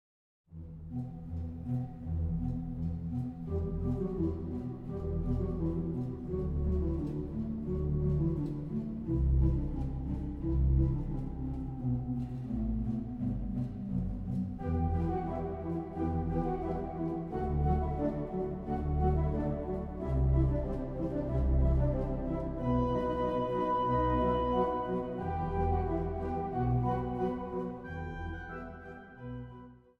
Zang | Kinderkoor
Zang | Solozang